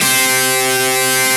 Index of /90_sSampleCDs/Roland LCDP02 Guitar and Bass/GTR_Distorted 1/GTR_Power Chords